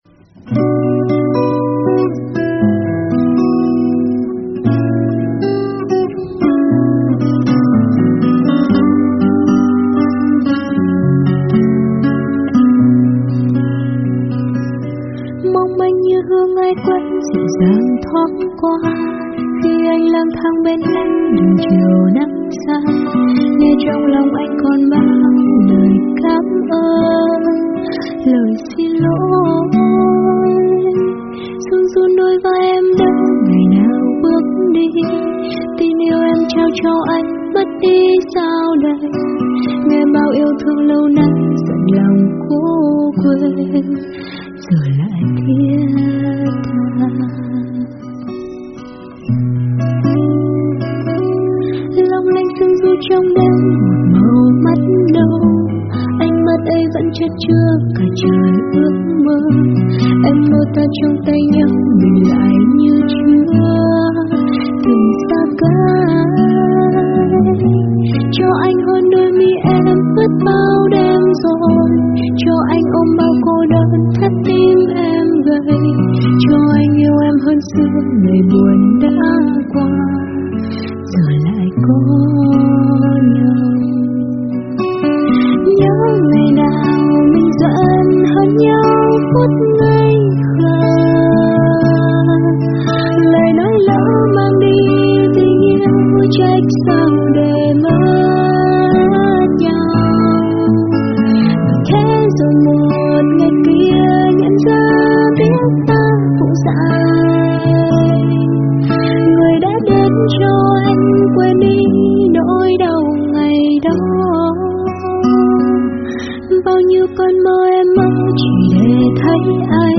Nhạc Sôi Động Cho Nhà Hàng, Nhạc Remix Cho Quán Bar